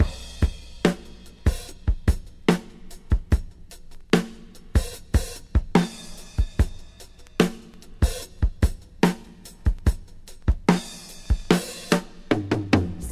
• 73 Bpm High Quality Drum Loop Sample A# Key.wav
Free breakbeat sample - kick tuned to the A# note. Loudest frequency: 764Hz
73-bpm-high-quality-drum-loop-sample-a-sharp-key-LYI.wav